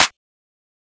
aw_clap_metro.wav